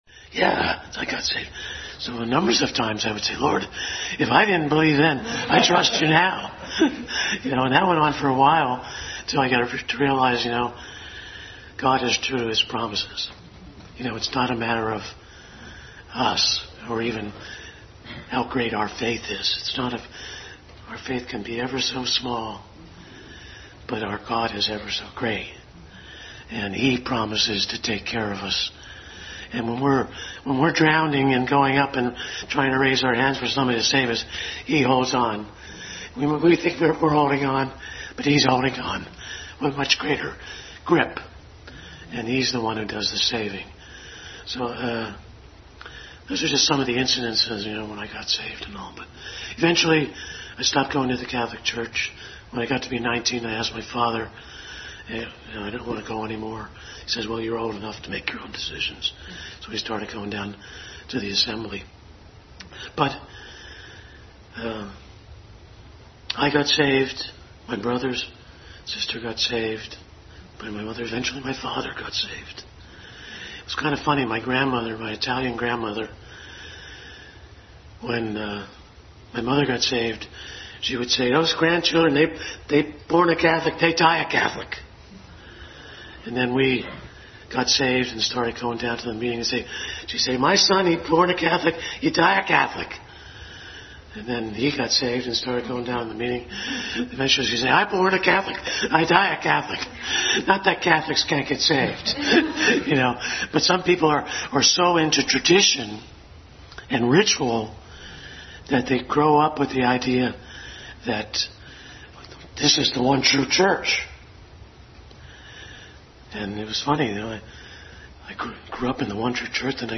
Family Bible Hour Testimony Time
Testimony Time